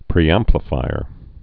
(prē-ămplə-fīər)